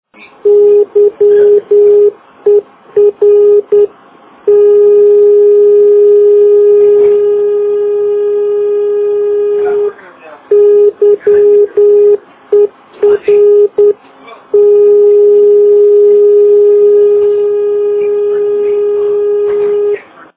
NDB Sounds
YER - 334 kHz (Fort Severn, Ontario) - Recorded at the transmitter.